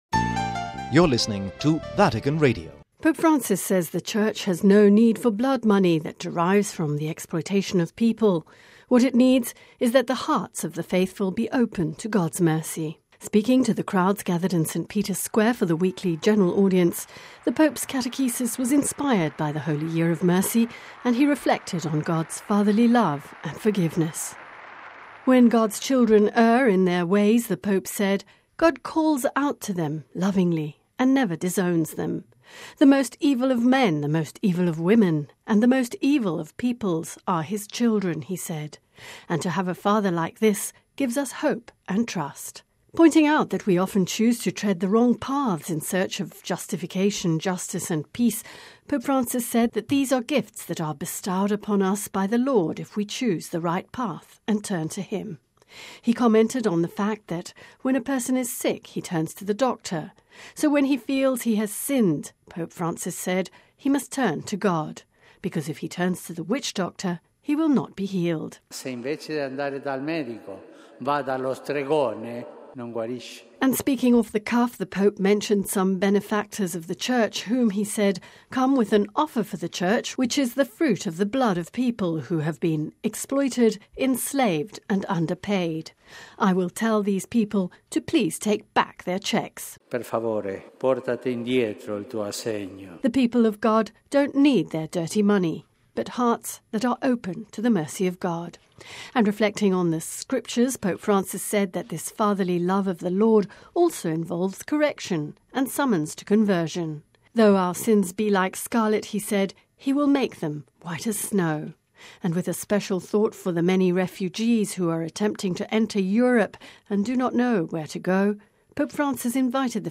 Speaking to the crowds gathered in St. Peter’s Square for the weekly General Audience, the Pope’s catechesis was inspired by the Holy Year of Mercy and he reflected on God’s fatherly love and forgiveness.